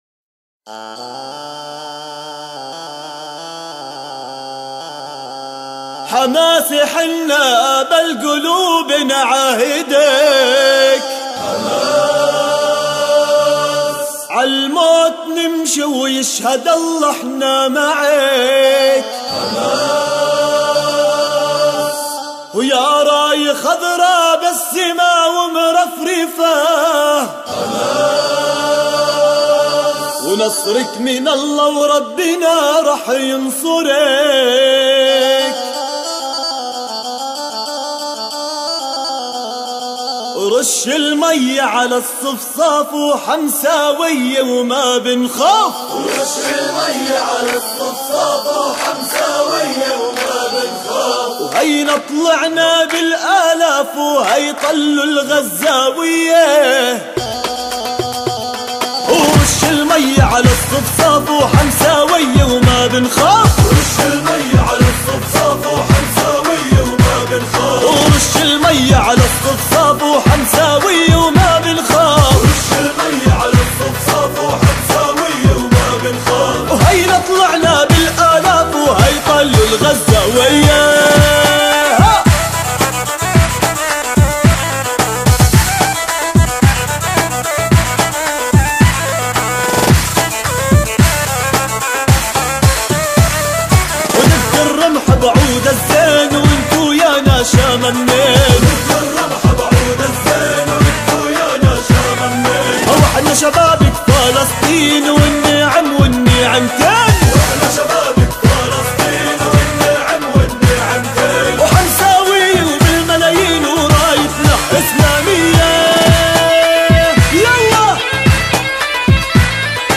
أناشيد فلسطينية...